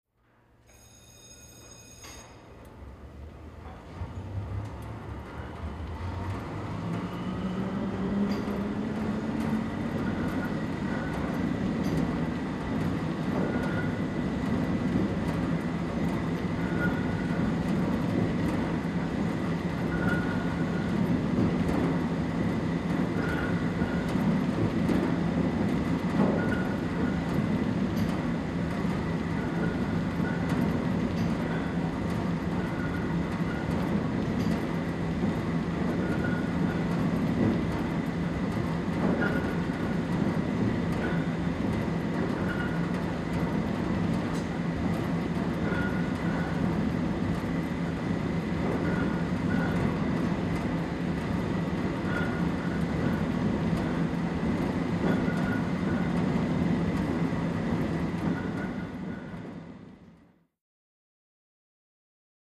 Cable Car Barn, W Slow Turning Machinery, Squeaky Wheels.